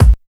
103 KICK 2.wav